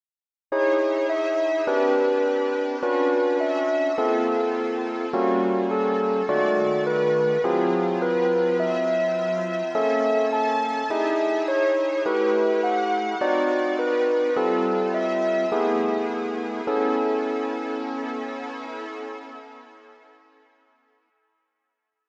響きは「すっきり・あいまい」という感じです。
という訳で、以下の実施例は上三声に接触する位置(付加音に近い形）を織り交ぜています。